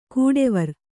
♪ kūḍevar